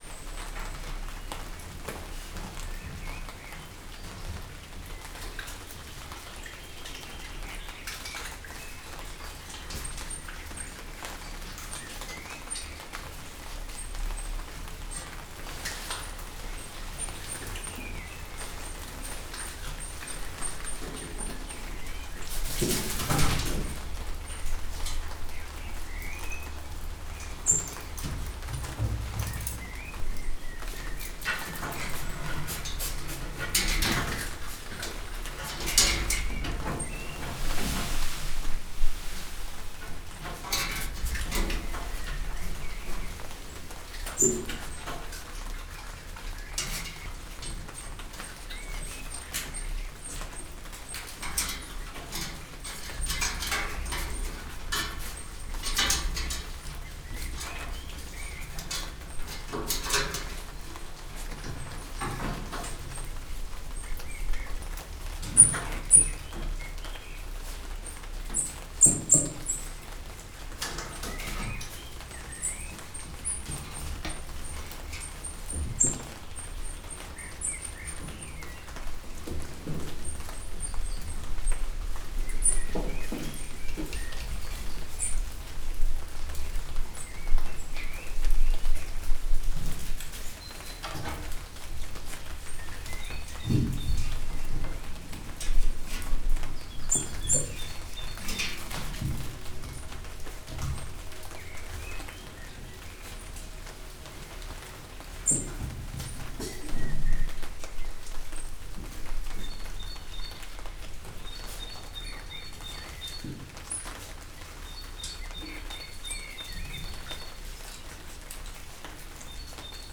ormanyosmedve
esznek02.06.WAV